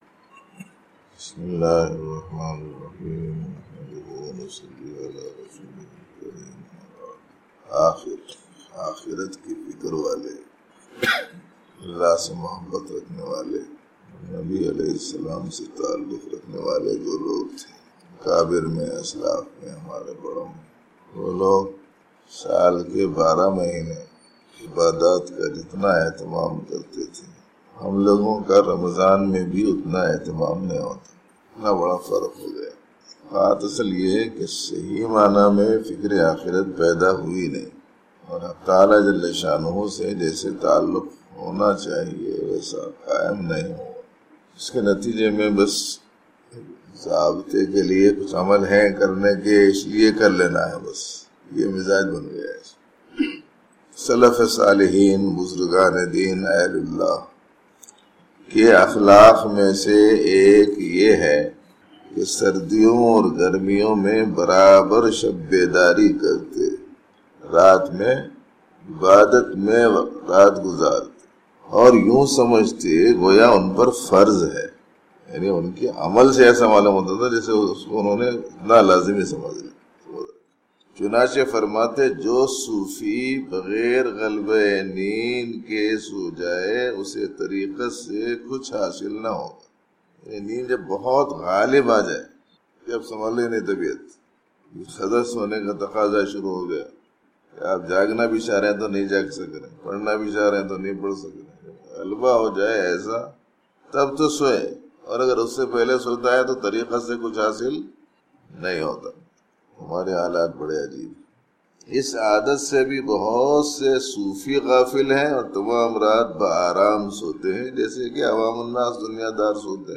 Recorded Date 26-Jul-2013, Khanqah e Jamaliya